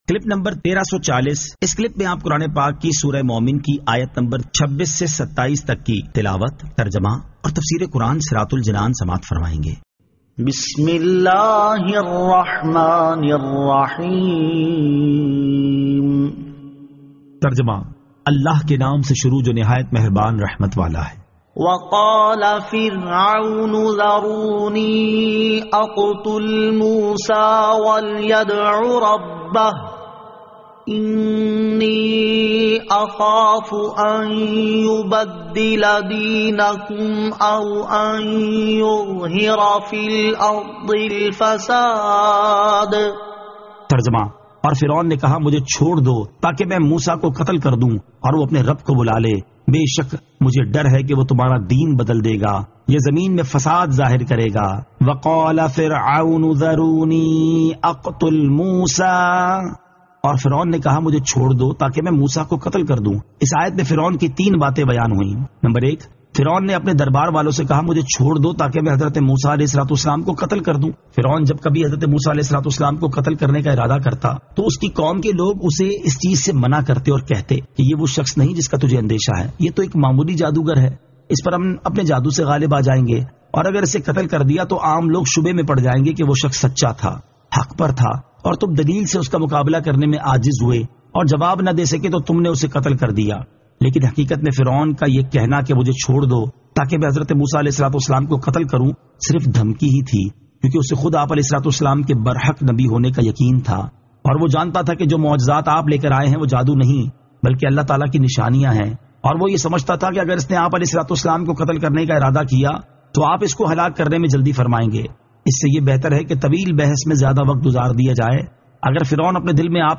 Surah Al-Mu'min 26 To 27 Tilawat , Tarjama , Tafseer